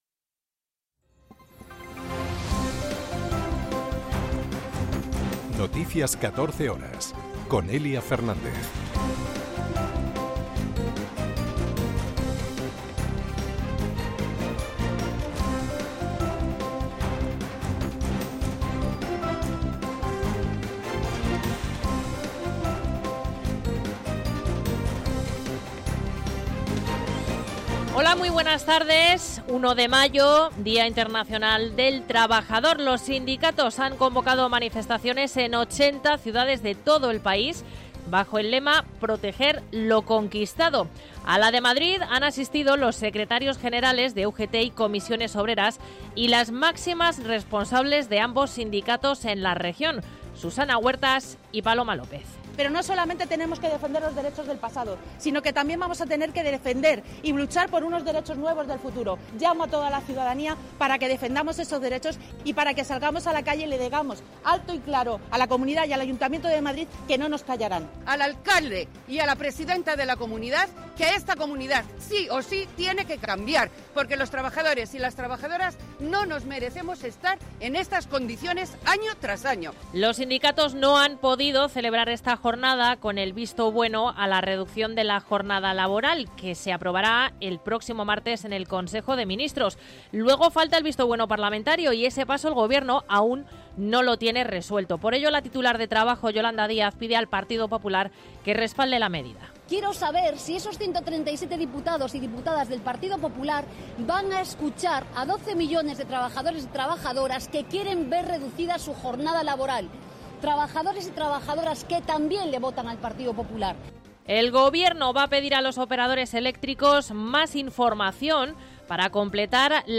en España y en el Mundo. 60 minutos de información diaria con los protagonistas del día, y conexiones en directo en los puntos que a esa hora son noticia